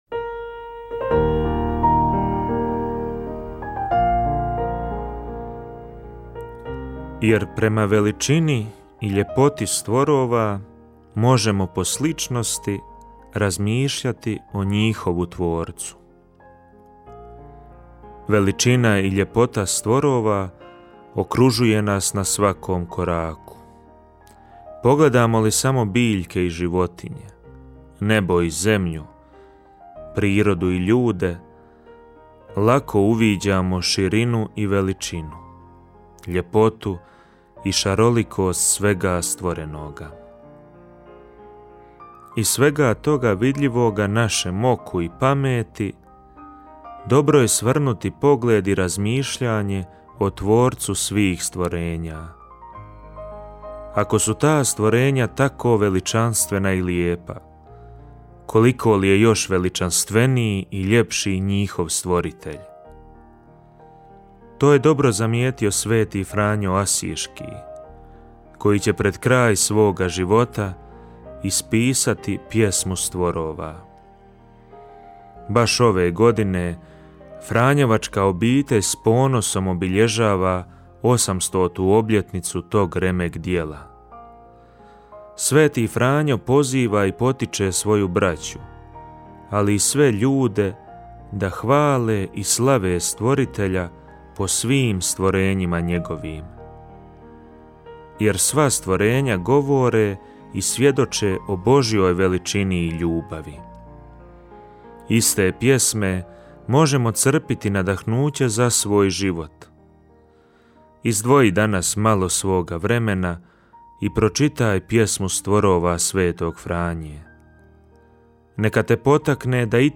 Emisije priređuju svećenici i časne sestre u tjednim ciklusima.